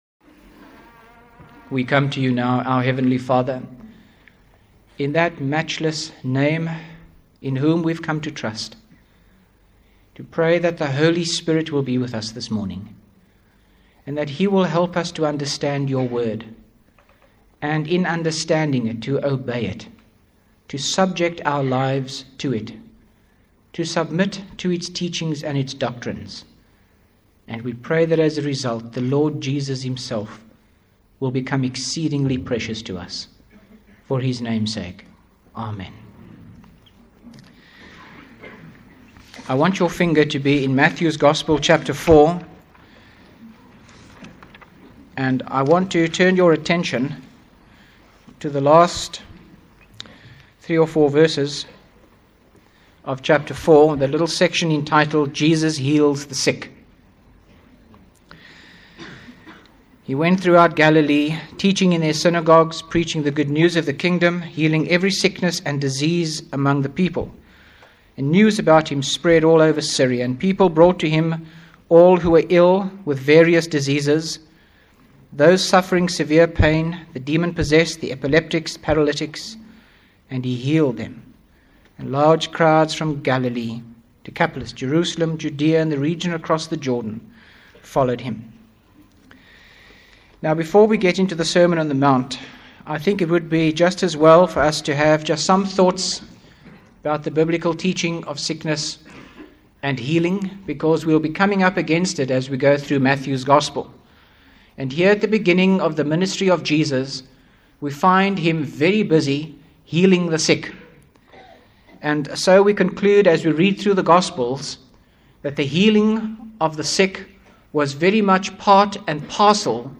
by Frank Retief | Jan 28, 2025 | Frank's Sermons (St James) | 0 comments